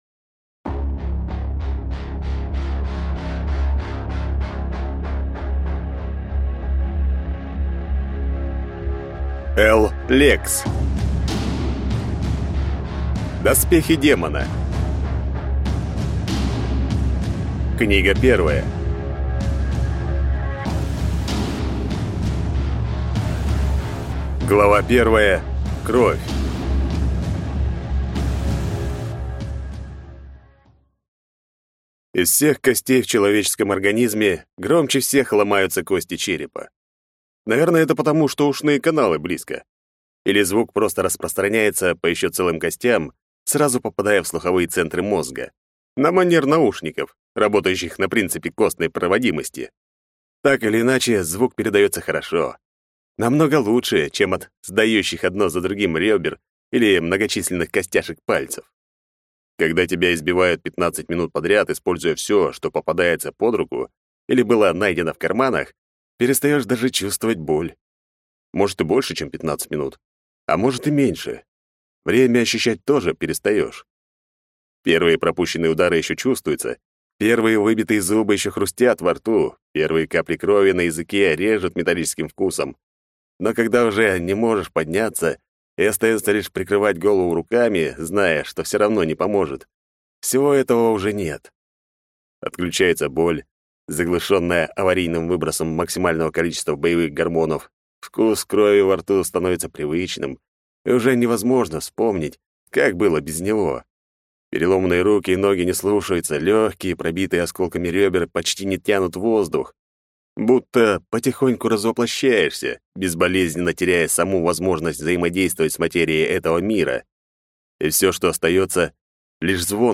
Аудиокнига Доспехи демона. Книга 1 | Библиотека аудиокниг